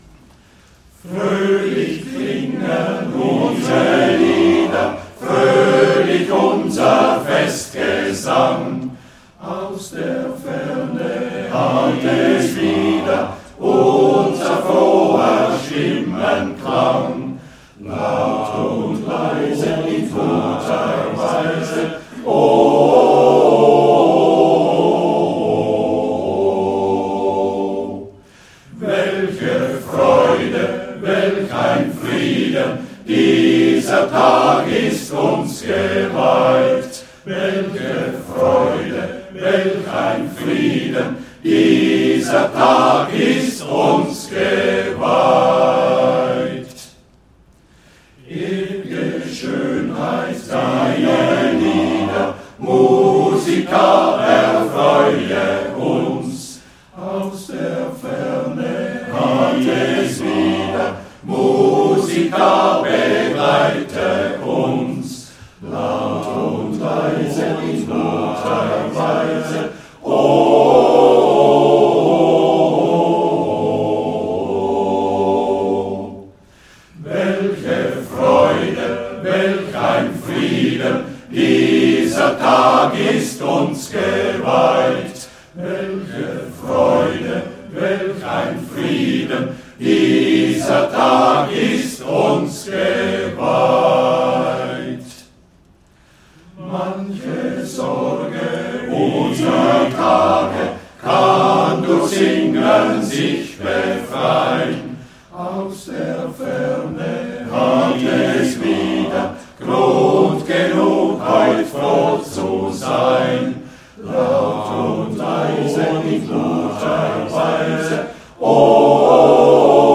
Gesamtbilder von unserem Chor seit 2010 bis ... heute
Schau dir die Bilder in Ruhe an und höre dazu unsere Melodien von der Serenade 2013 (unten) und vom Maikonzert 2015 (oben)